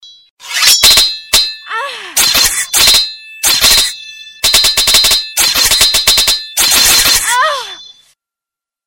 sword-slices_24928.mp3